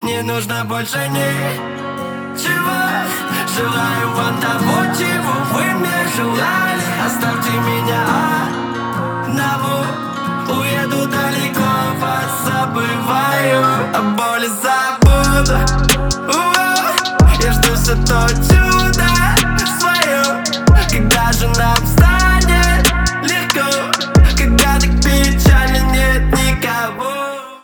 • Качество: 320, Stereo
лирика
душевные
русский рэп